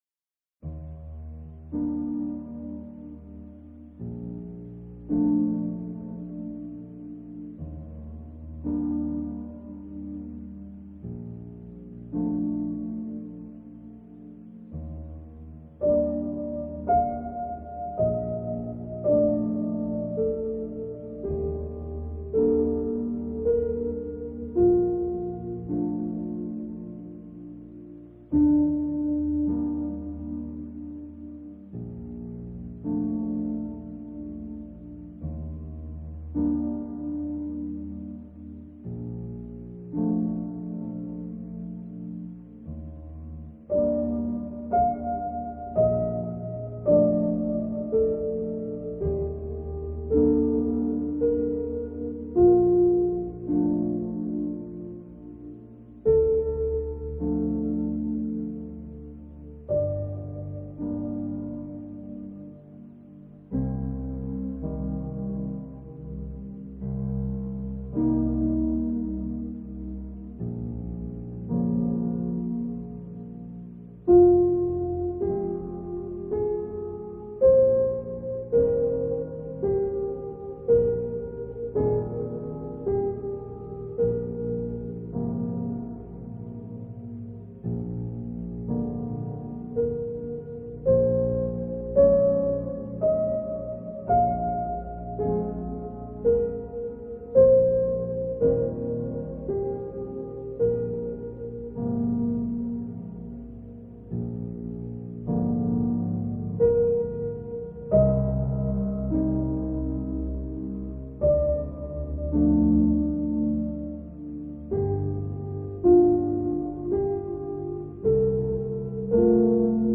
Gymnopédie_No._1_slowed_and_reverbed.mp3